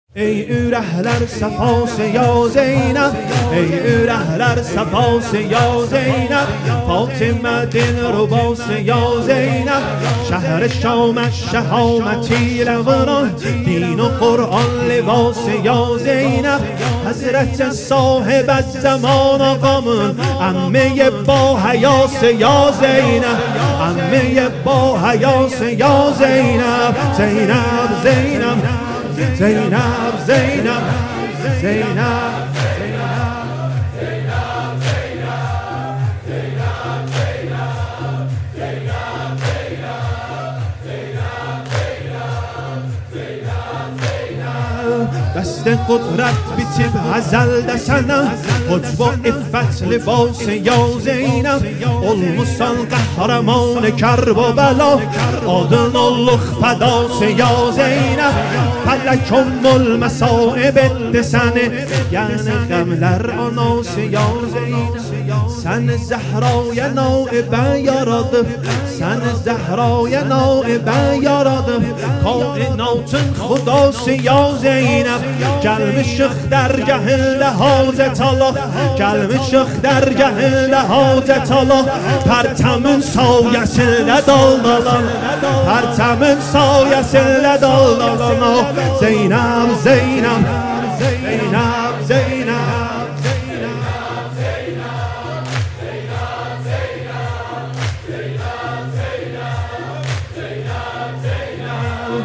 سینه زنی واحد
مراسم شب شهادت حضرت عقیلة العرب(س)